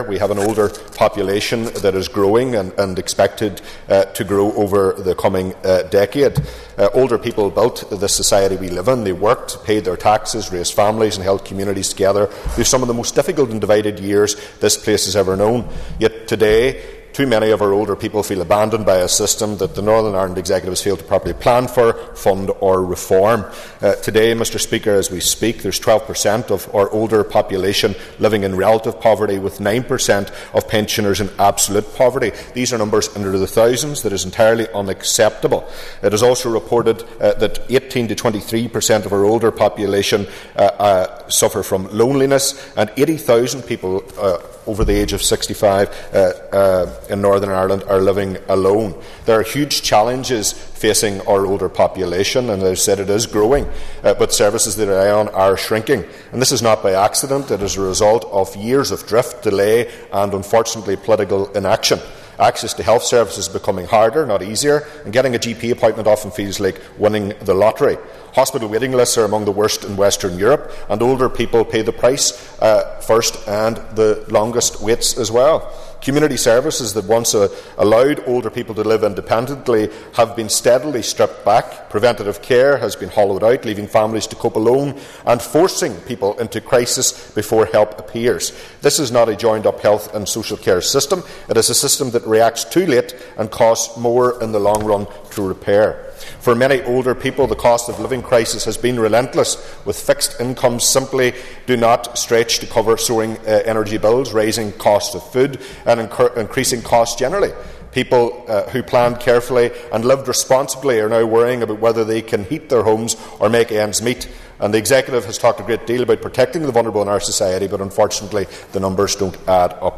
West Tyrone MLA Daniel McCrossan told members the Assembly must acknowledge that a lack of planning has led to a situation where many older people are living in poverty.